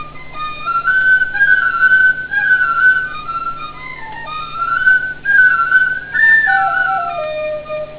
Éist linn ag canadh.....amhráin1.